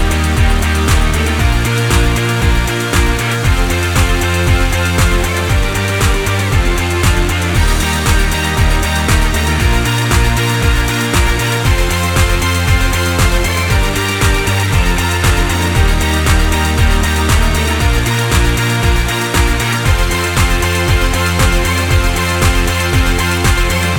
Duet Version Pop (2010s) 3:17 Buy £1.50